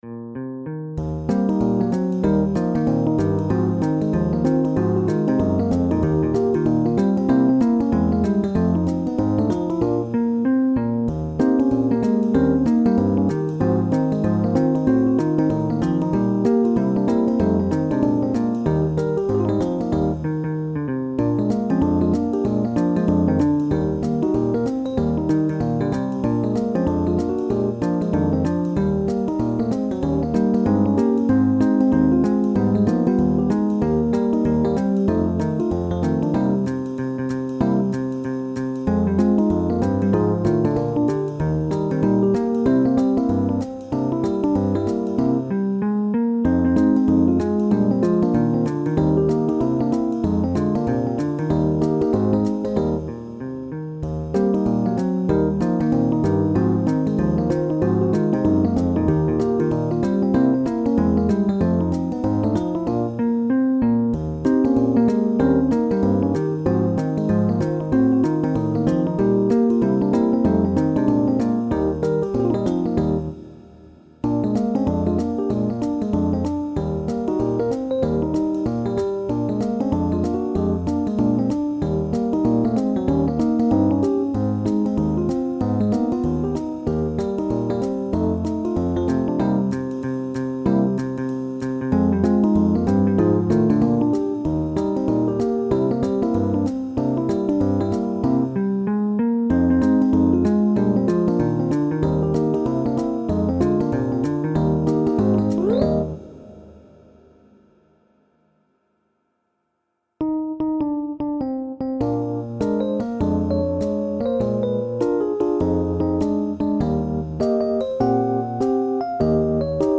SSATB | SSAAB